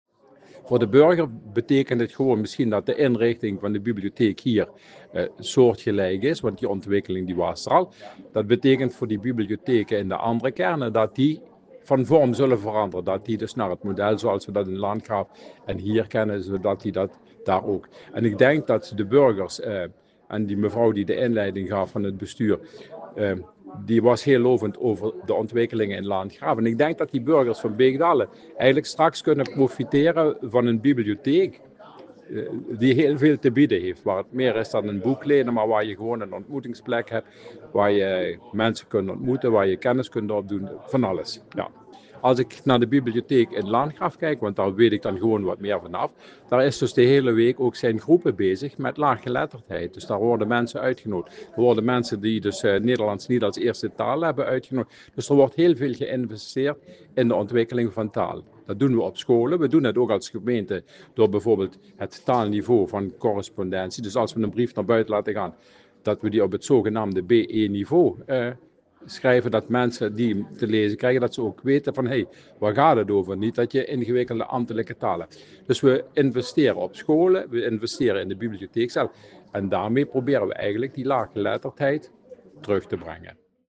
Wethouder Alex Schiffelers van Gemeente Landgraaf